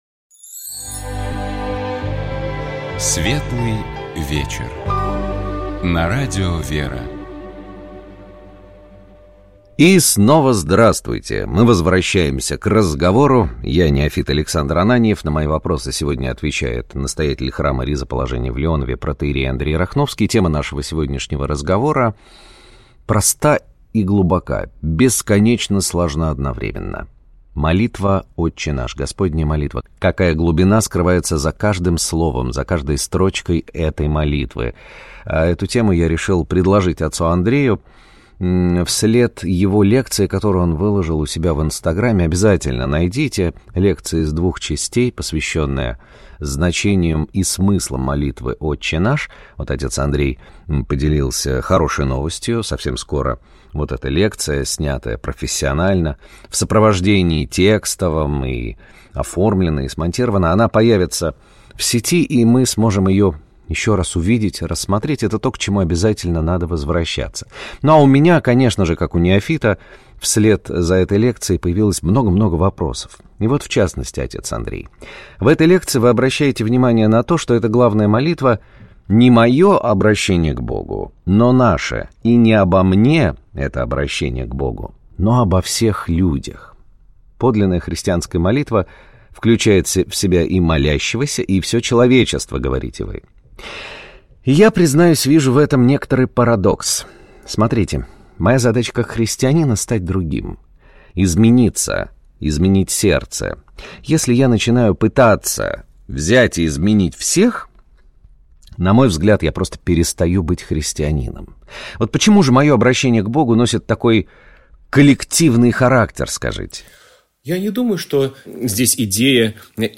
Разговор шел о смыслах и значении молитвы «Отче наш».